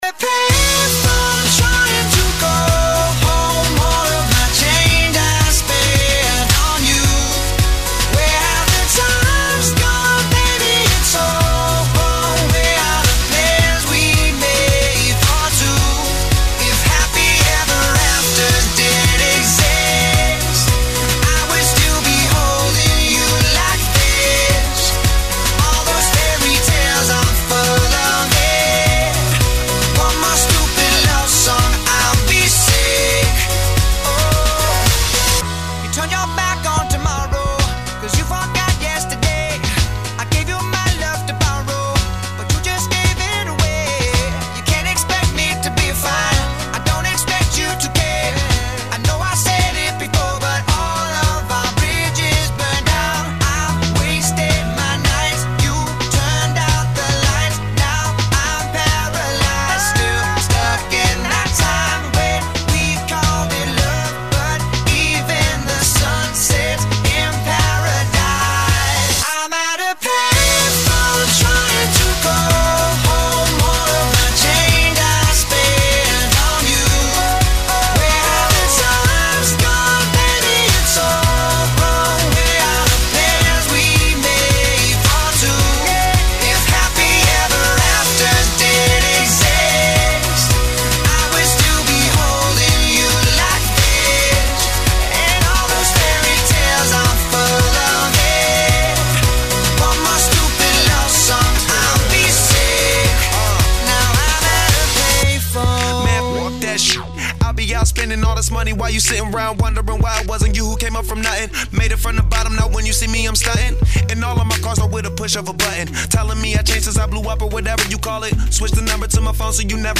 TALKSHOW BATAN DI RADIO EL JOHN PANGKAL PINANG, JUDUL : BEASISWA PRESERVASI IPTEK NUKLIR - Repositori Karya
AUDIO_TALKSHOW DI RADIO EL JOHN_BEASISWA PRESERVASI IPTEK NUKLIR_PDK_2012.mp3